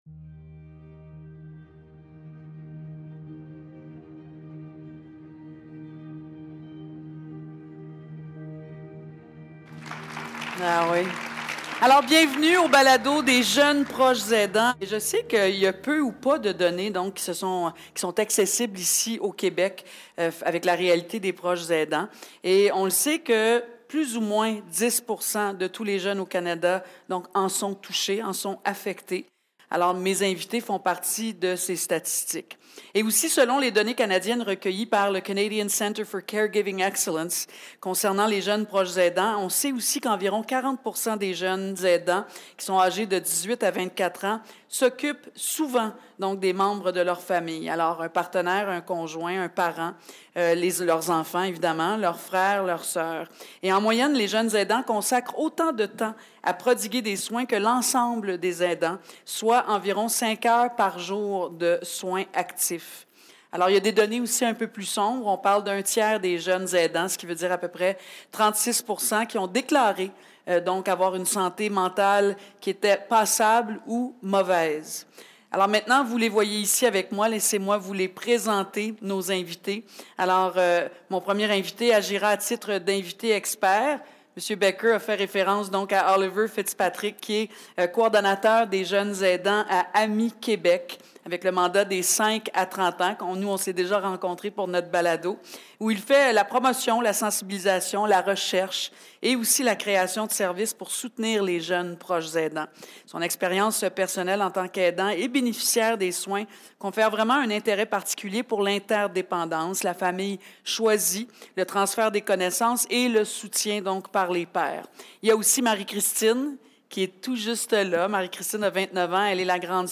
Ces jeunes témoignent de leur quotidien en tant qu'aidants familiaux, expliquant comment ils jonglent entre leurs études, leur vie sociale et leurs responsabilités d'aidant. Ils partagent leurs défis, leurs demandes d'aide et leurs stratégies pour trouver un équilibre.